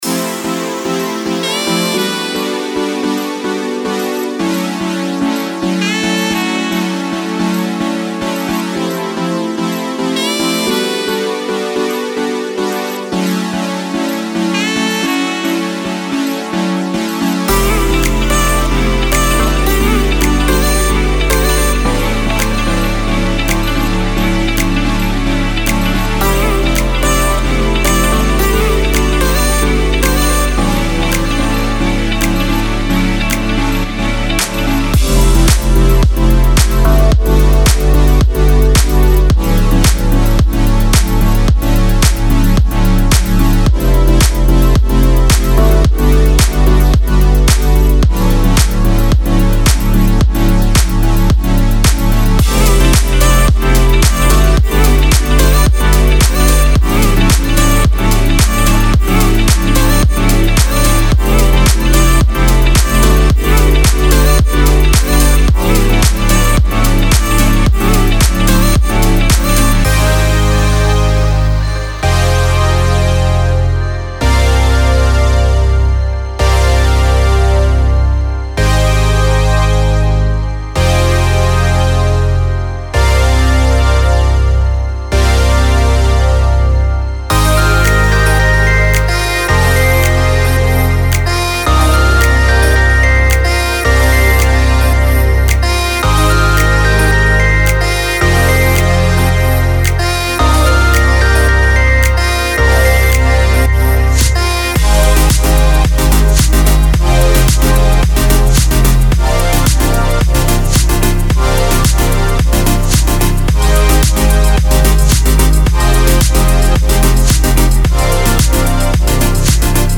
Sweet and melodic future funk sounds.